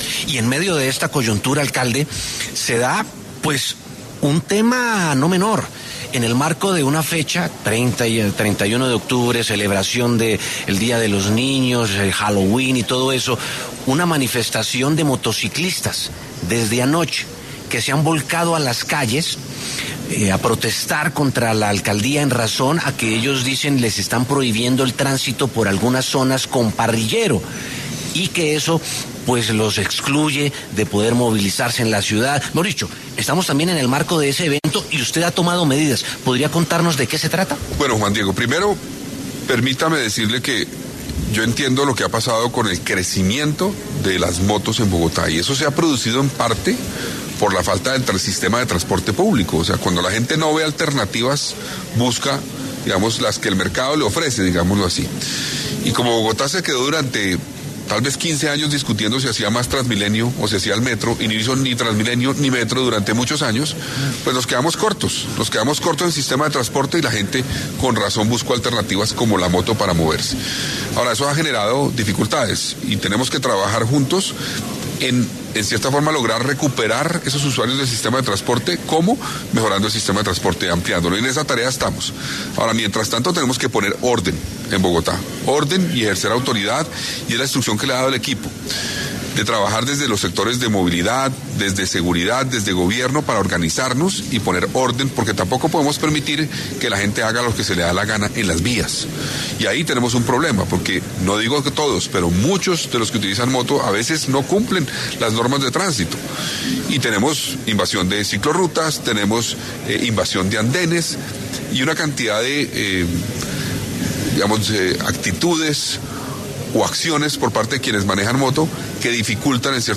En los micrófonos de W Radio, el alcalde Carlos Fernando Galán respondió a las inconformidades que existen entre el genio motero de la ciudad por las restricciones de movilidad que implementó la Alcaldía de Bogotá en medio del puente festivo de Halloween.